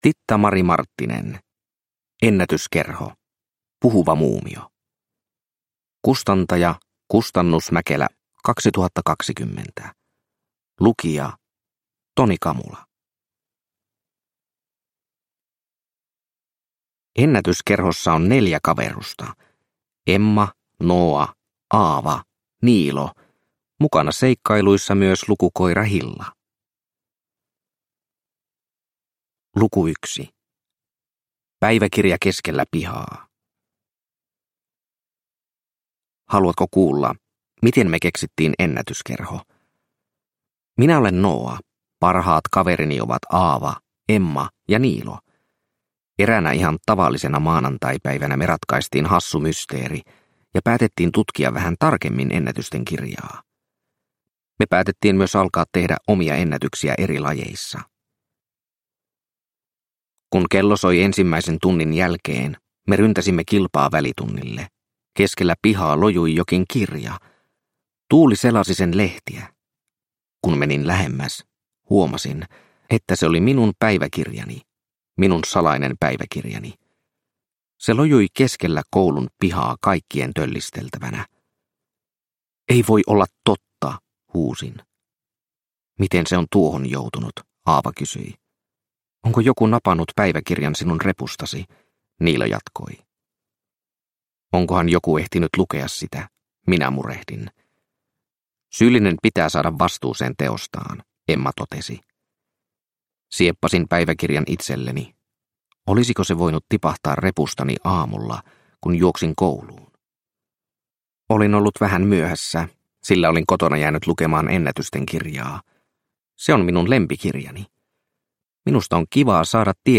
Puhuva muumio – Ljudbok – Laddas ner